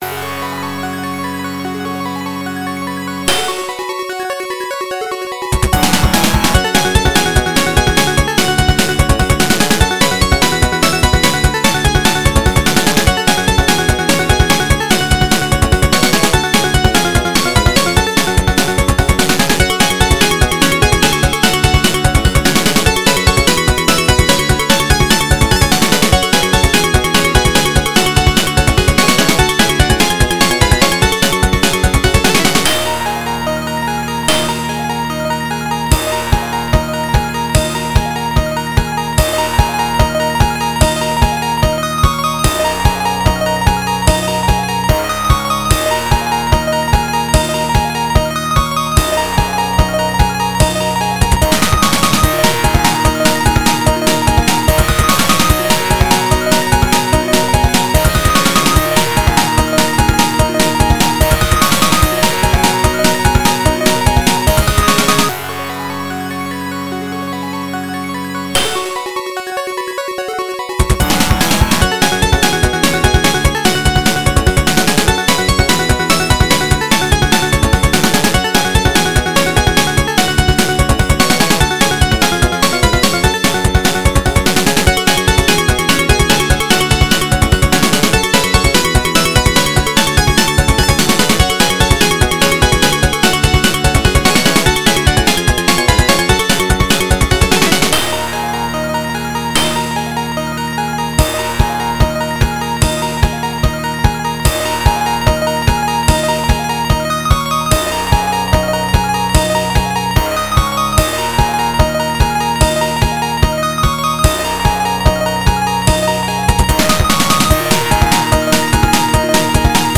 原游戏FM86版，由PMDPlay导出。